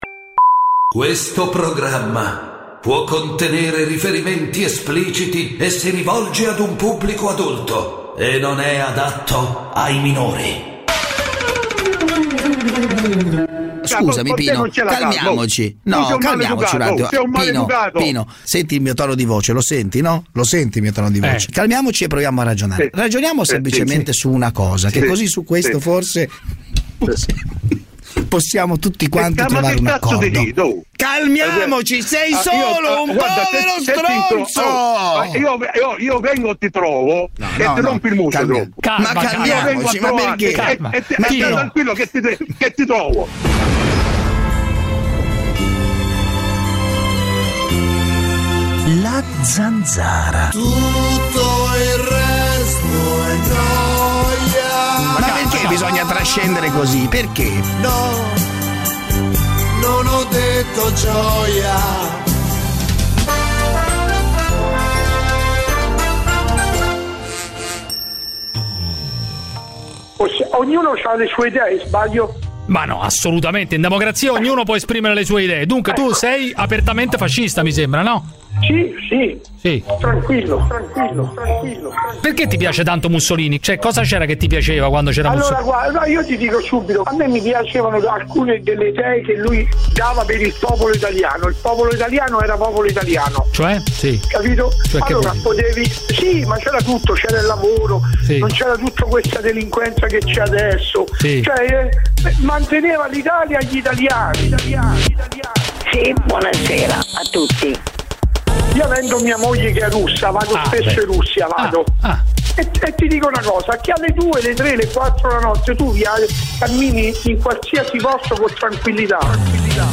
… continue reading 2022 episoder # Italia Attualità # News Talk # Notizie # Radio 24